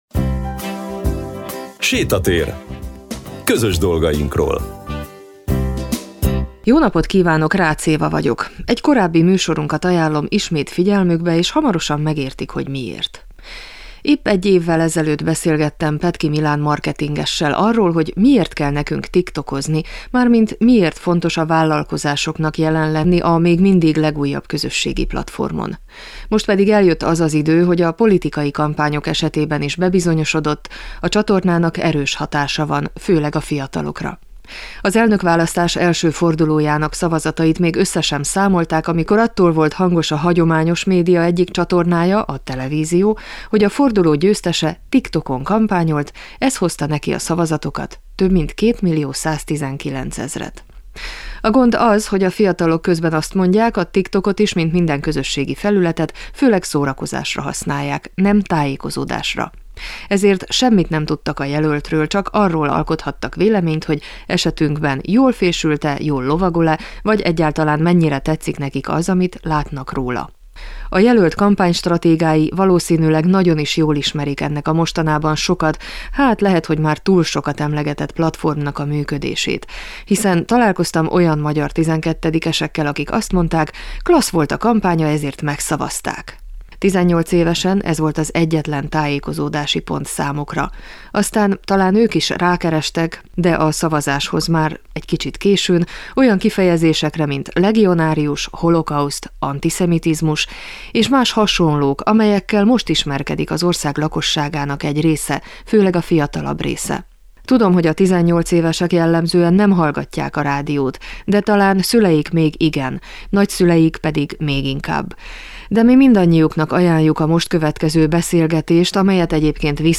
De mi mindannyiuknak ajánljuk a beszélgetést amely arról szól, hogyan működik a TikTok, és ezen belül hogyan működik a marketing.